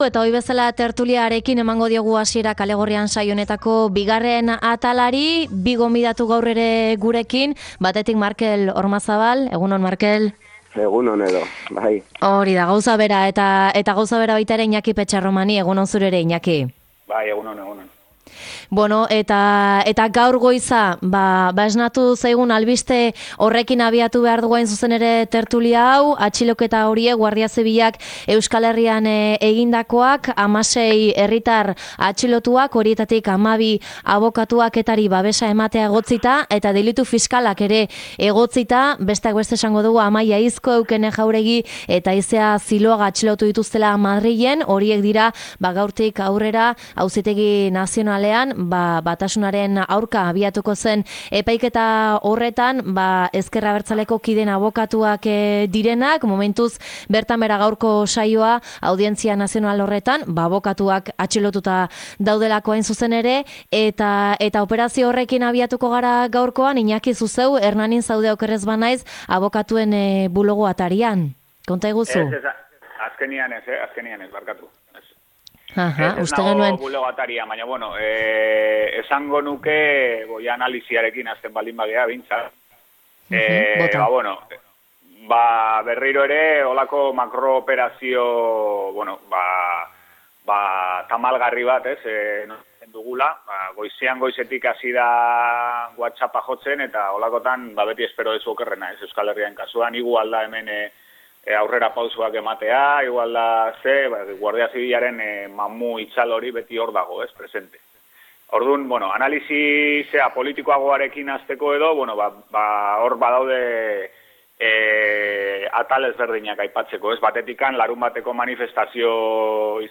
Tertulia: polizia operazioa eta Bilboko mobilizazioa